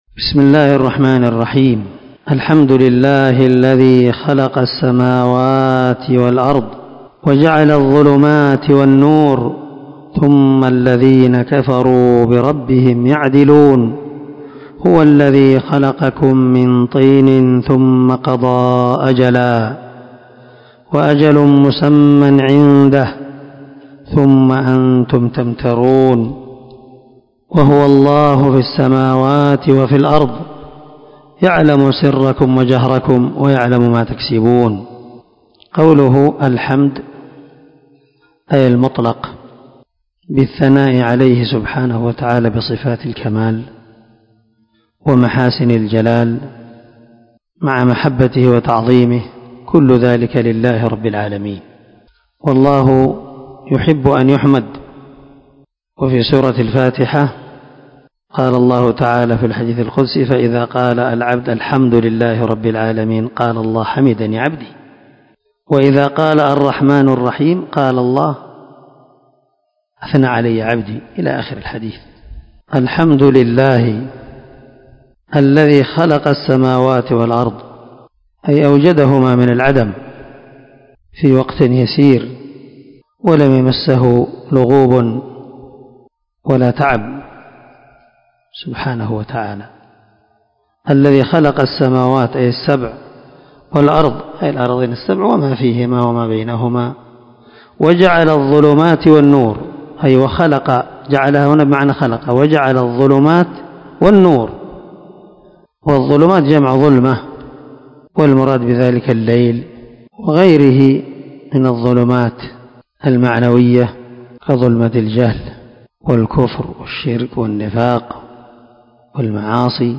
سلسلة_الدروس_العلمية
✒ دار الحديث- المَحاوِلة- الصبيحة.